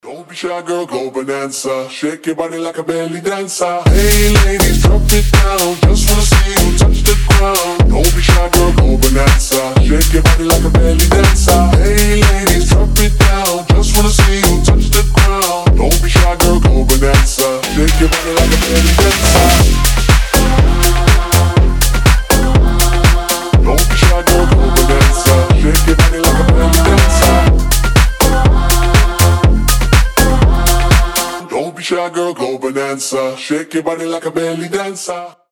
• Качество: 320, Stereo
ритмичные
качающие
house
ремиксы
slap house